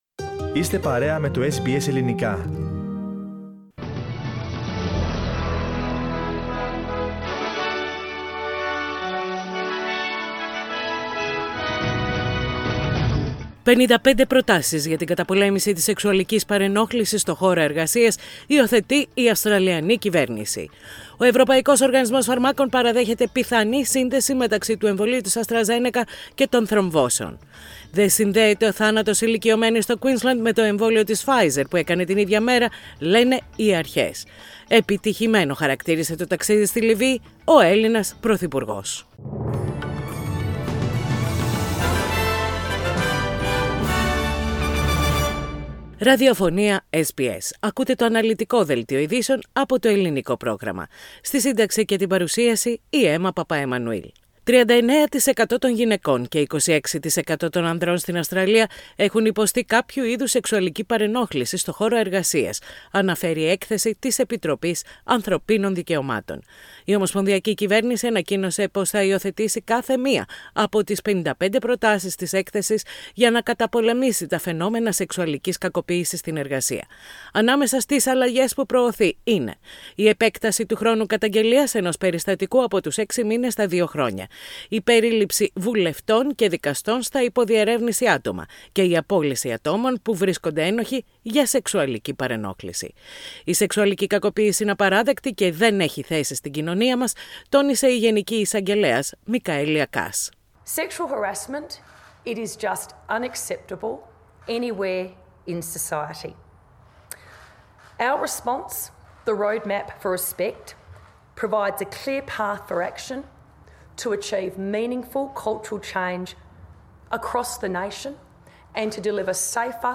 Ειδήσεις στα Ελληνικά - Πέμπτη 8.4.21
Οι κυριότερες ειδήσεις της ημέρας από το Ελληνικό πρόγραμμα της ραδιοφωνίας SBS.